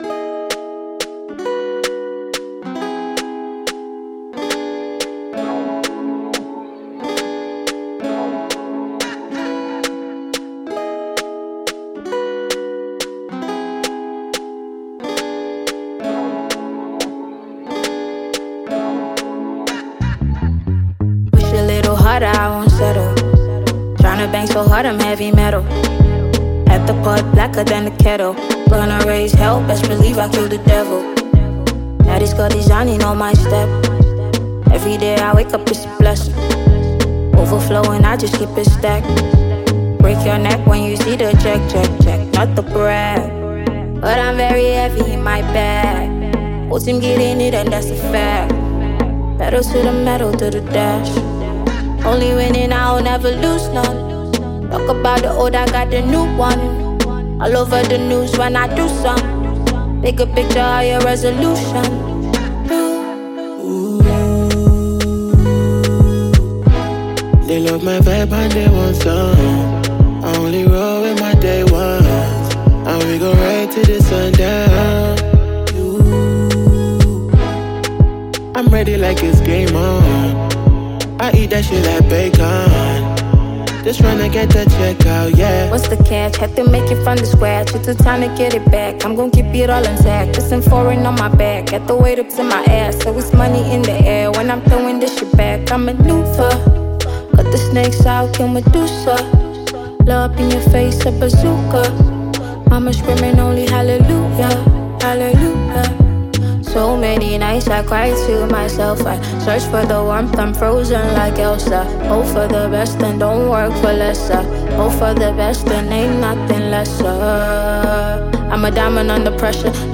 Upcoming Nigerian female singer